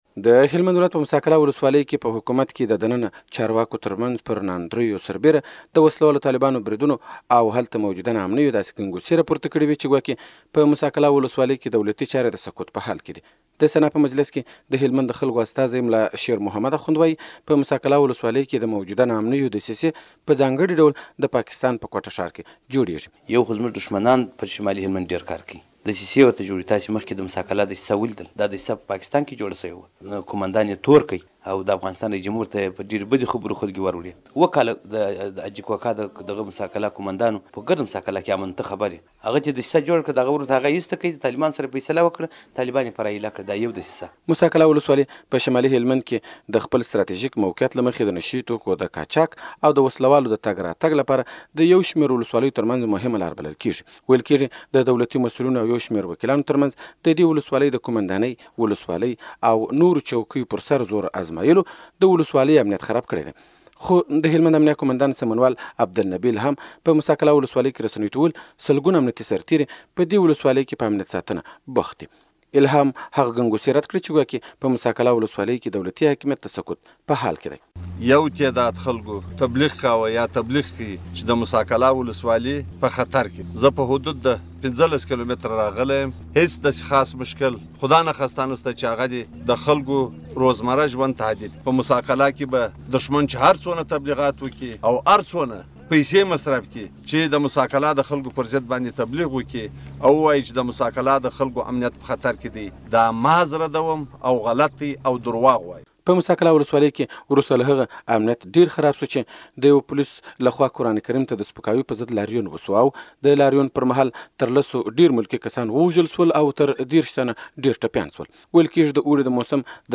راپور